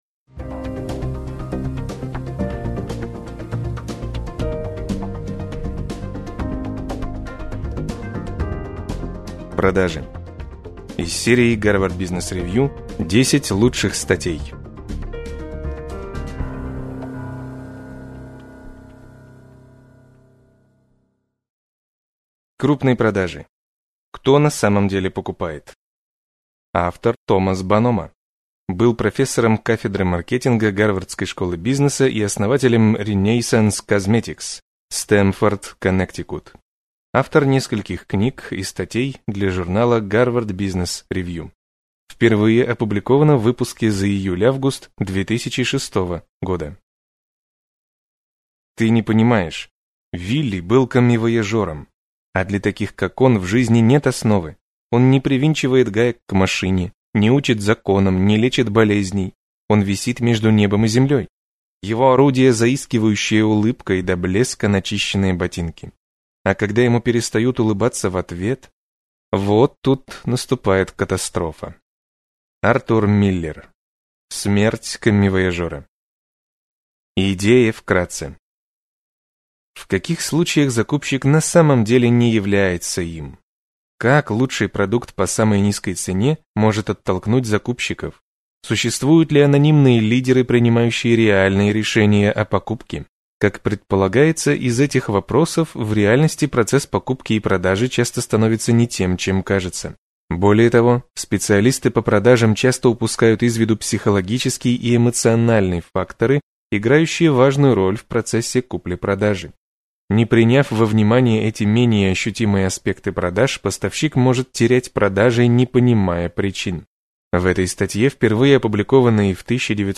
Аудиокнига Продажи | Библиотека аудиокниг